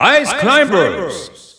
Announcer pronouncing Ice Climbers in French.
Ice_Climbers_French_Announcer_SSBU.wav